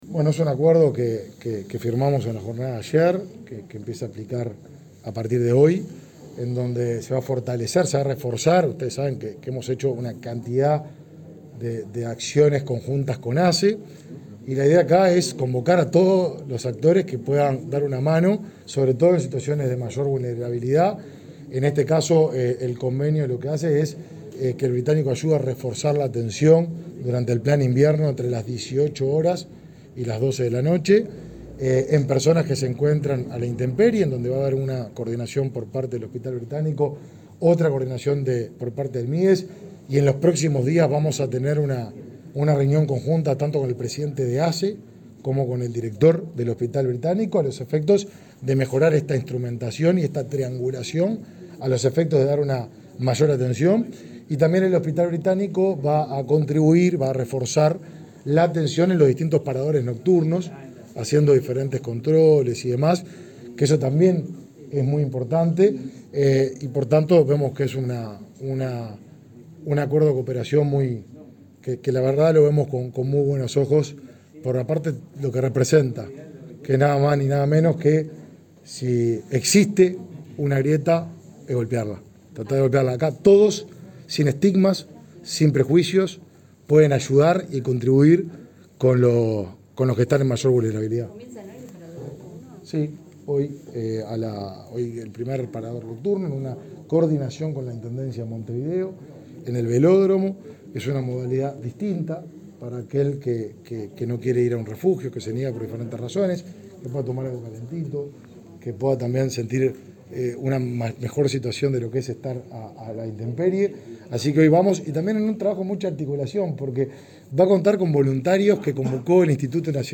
Declaraciones de Martín Lema sobre acuerdo de cooperación entre el Mides y Hospital Británico